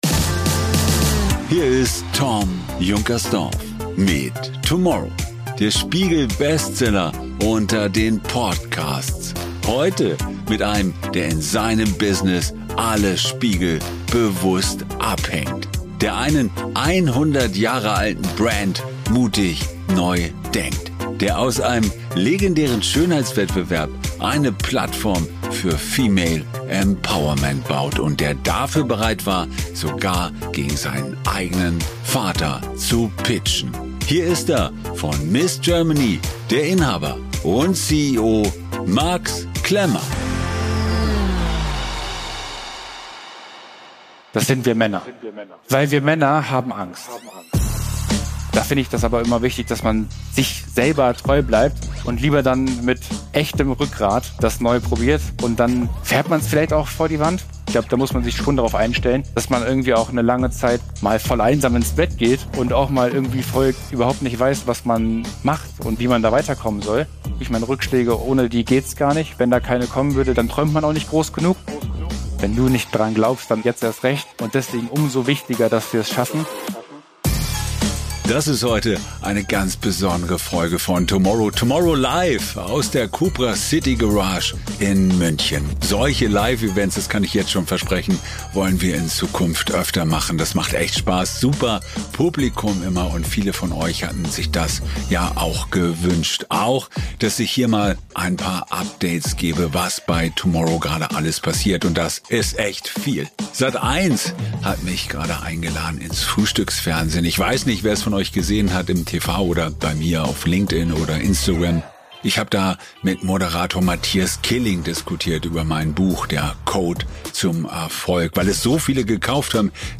Die ganze Story jetzt hier im TOMorrow Live-Podcast aus der CUPRA CITY Garage München.